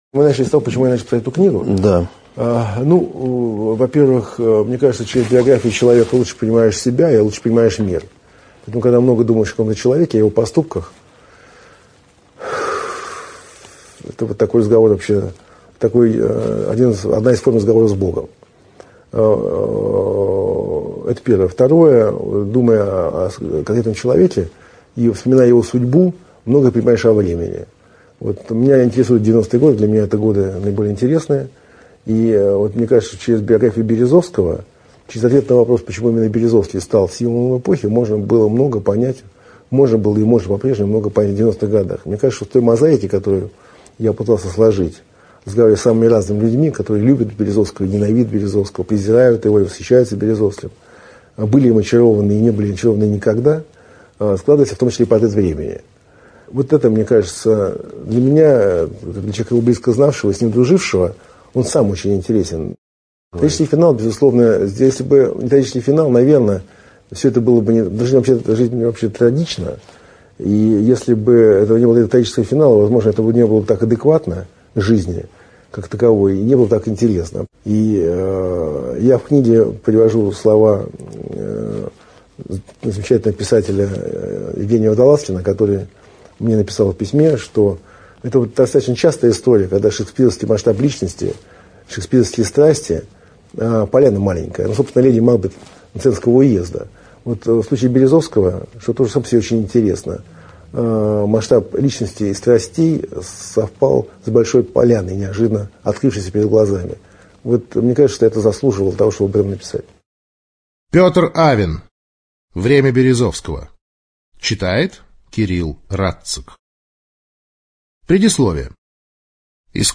ЖанрПублицистика, Биографии и мемуары